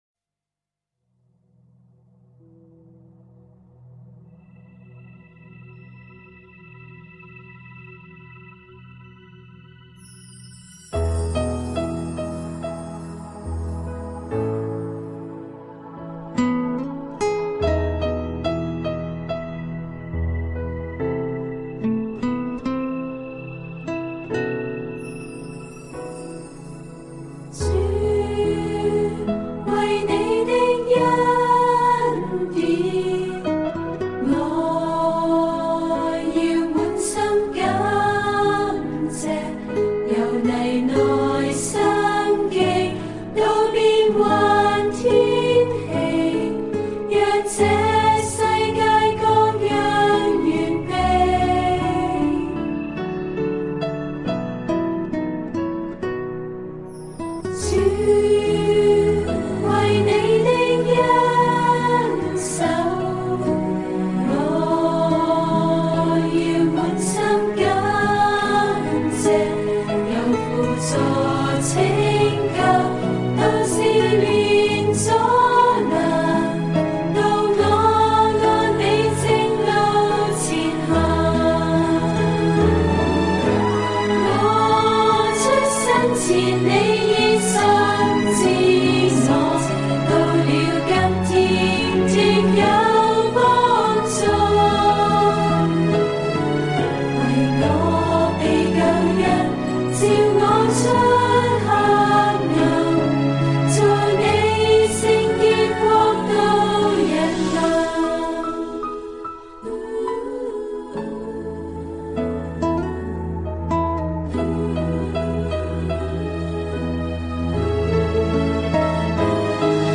赞美诗｜以感恩为祭